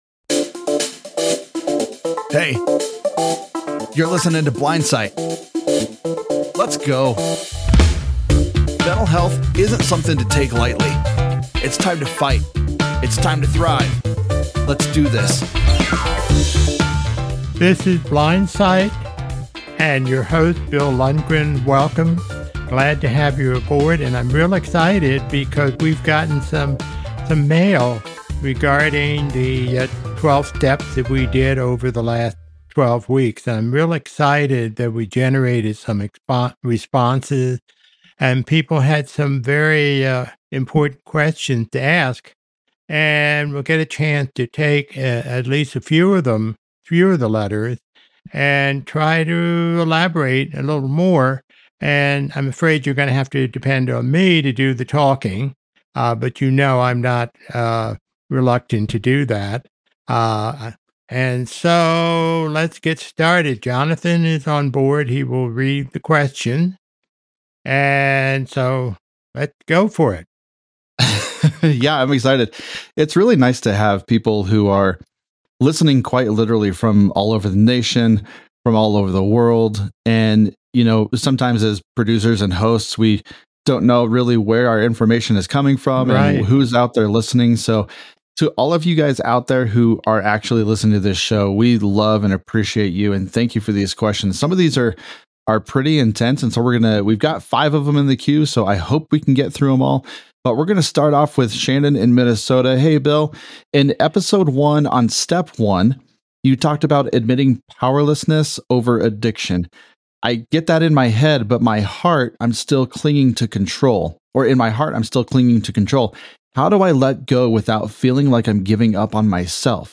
a mental health counselor